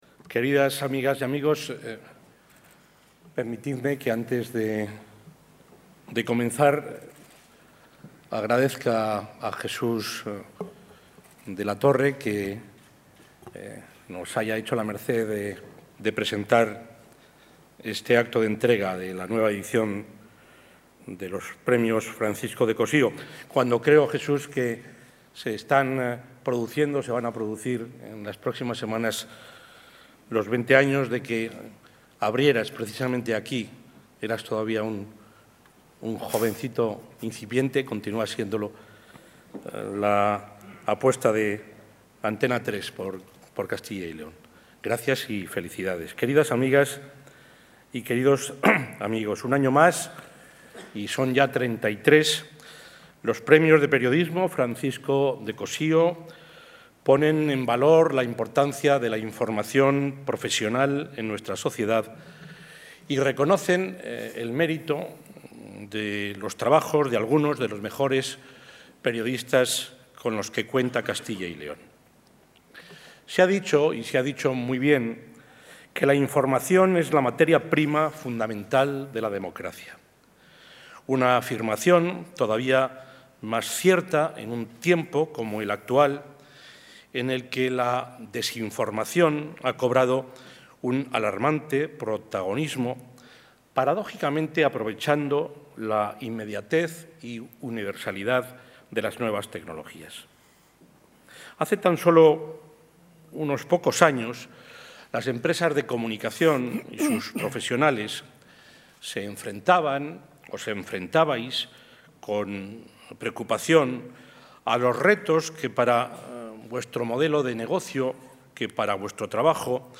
Presidente de la Junta de Castilla y León.
El presidente de la Junta de Castilla y León, Juan Vicente Herrera, ha presidido hoy la entrega del XXXIII Premio Francisco de Cossío, en un acto en el que ha destacado que la respuesta a estas nuevas amenazas pasa por seguir apostando por un periodismo libre y profesional, basado en la credibilidad, la transparencia y la ética profesional.